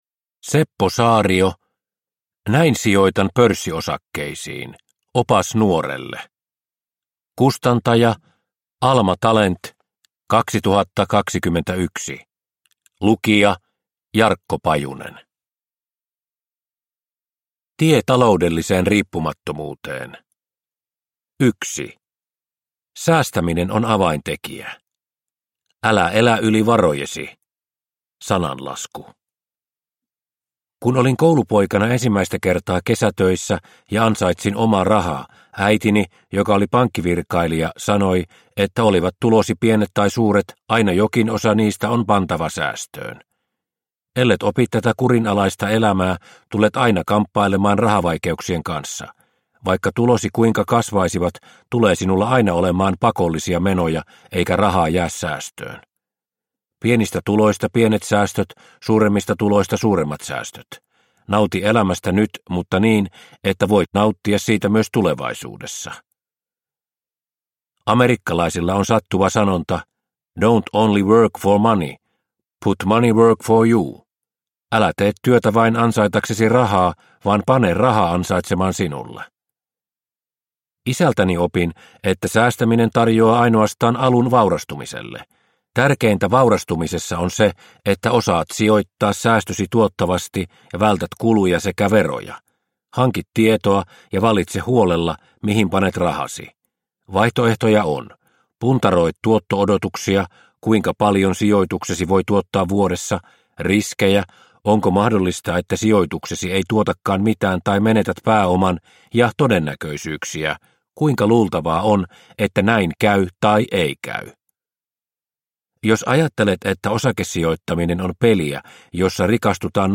Näin sijoitan pörssiosakkeisiin - opas nuorelle – Ljudbok – Laddas ner